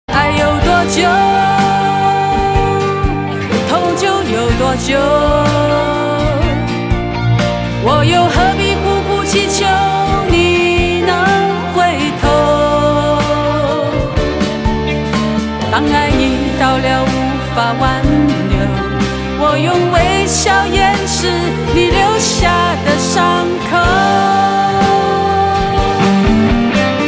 华语歌曲
国语